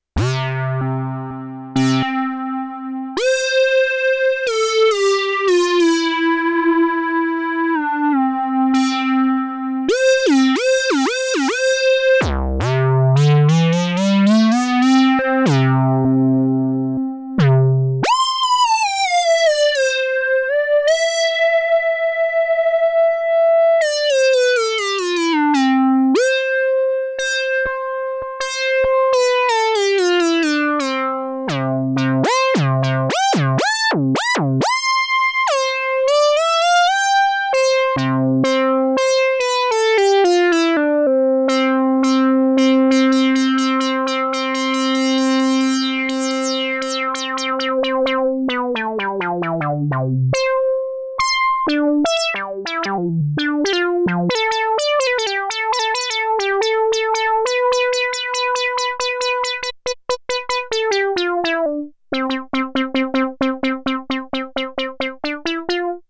DIY Minimoog Model D
Some sound demos:
Not very musical.  Just so you can hear some of the sounds.